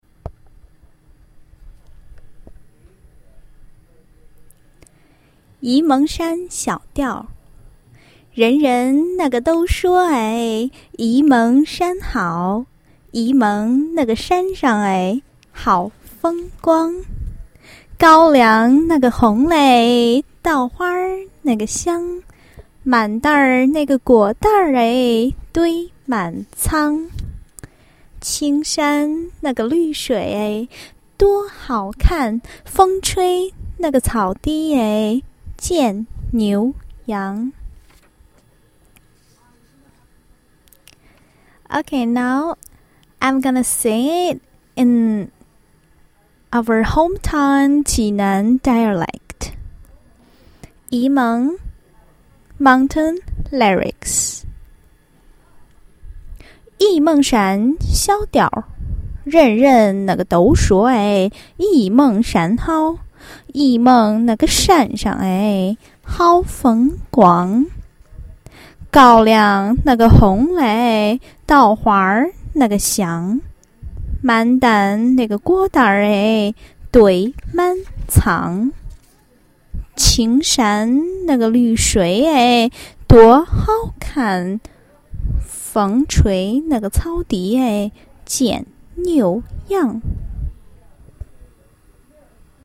Lyrics (Standard Mandarin),
Yi Meng Lyrics Standard.mp3